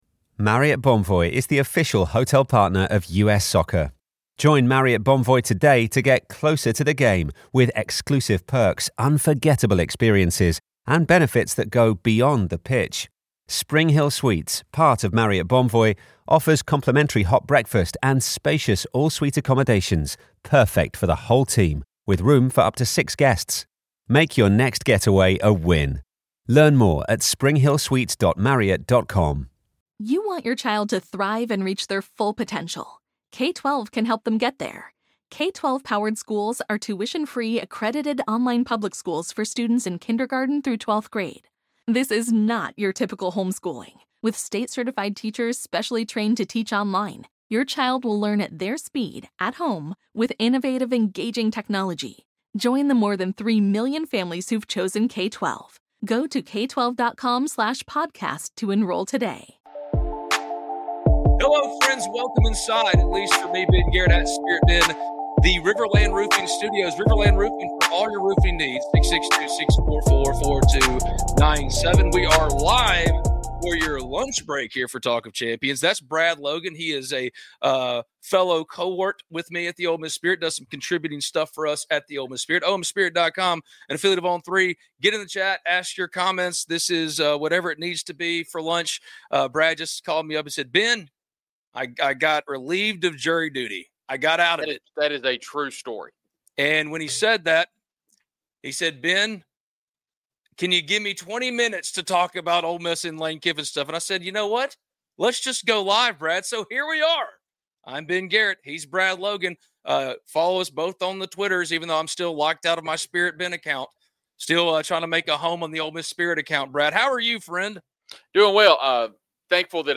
We are live for your lunch break here for Talk of Champions.